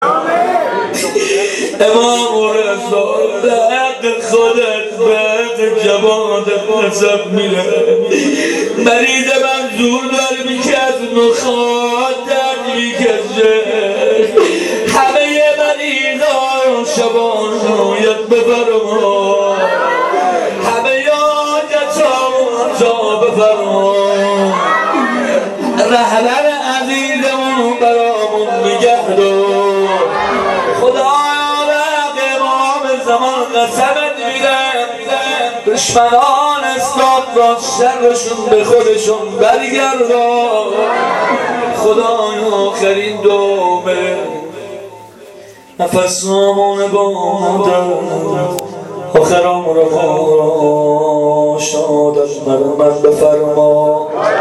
روضه-امام-رضا-حاجی.mp3